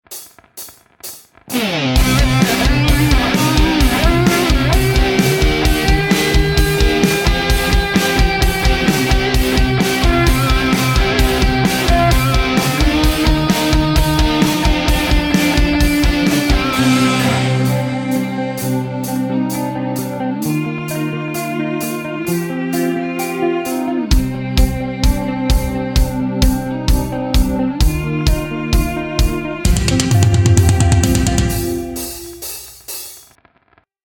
Дело в том, что там хорошо прослеживается стиль и качество.